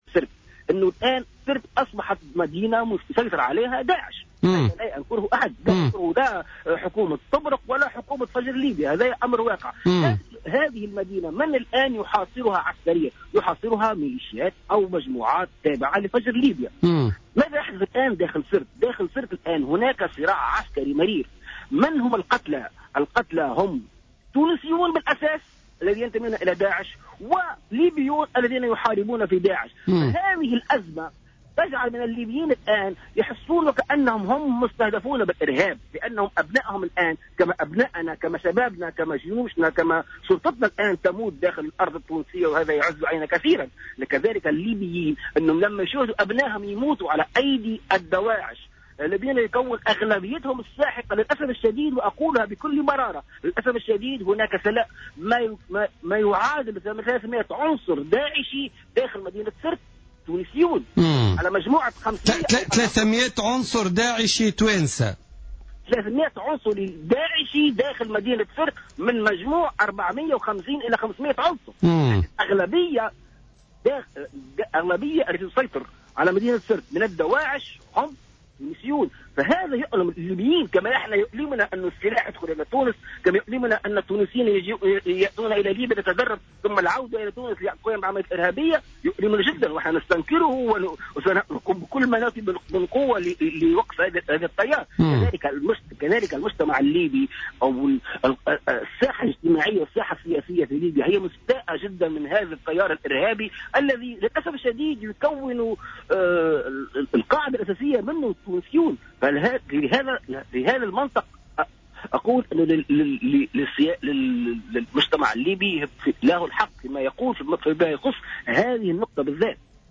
مداخلة